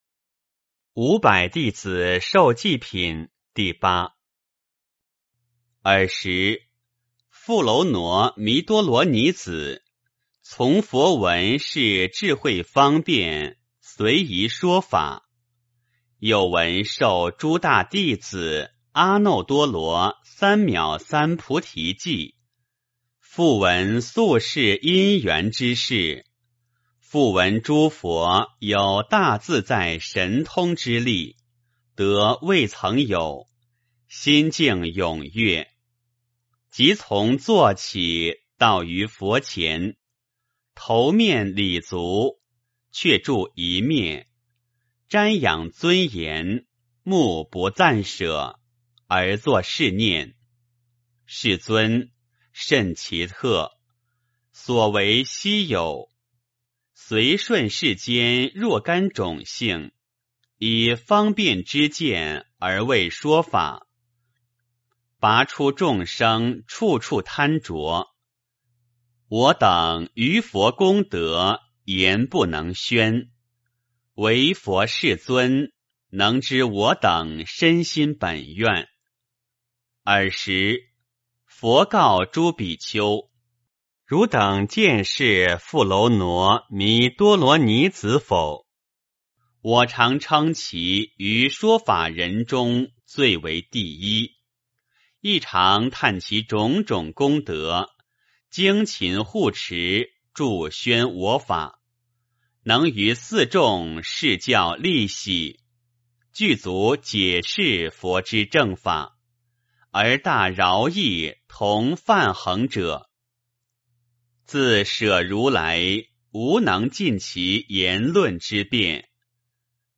法华经-五百弟子受记品 - 诵经 - 云佛论坛